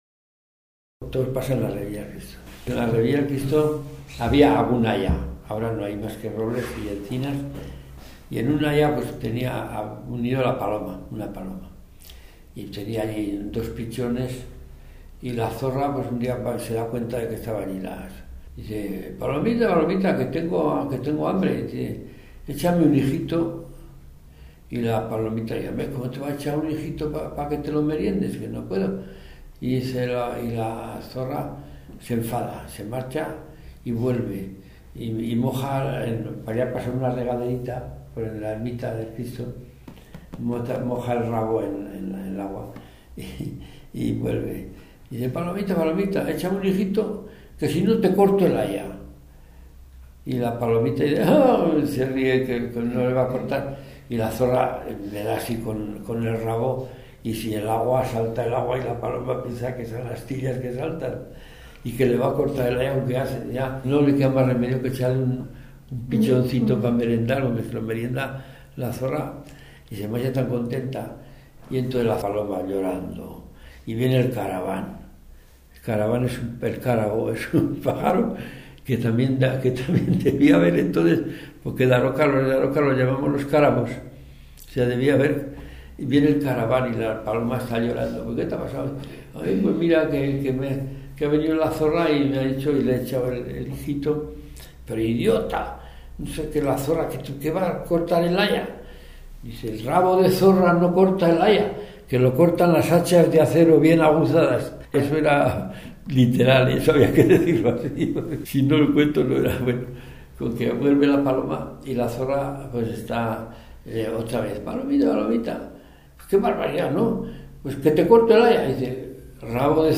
Clasificación: Cuentos
Lugar y fecha de recogida: Logroño, 6 de marzo de 2017